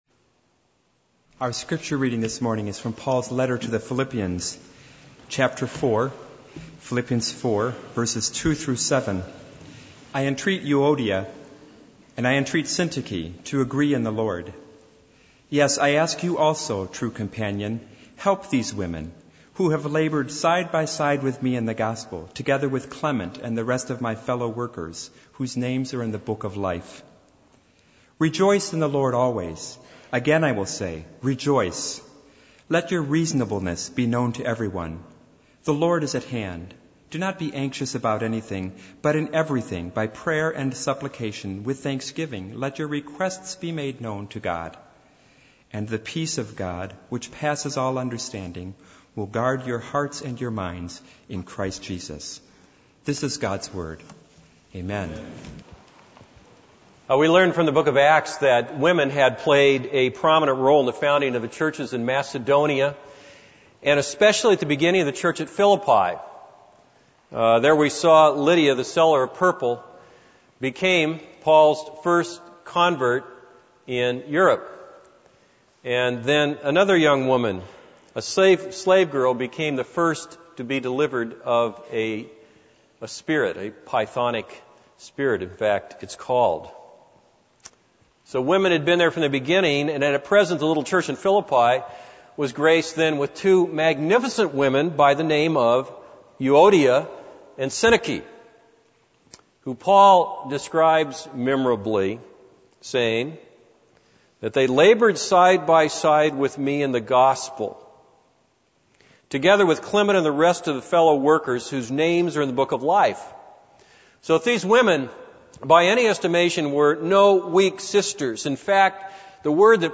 This is a sermon on Philippians 4:2-7.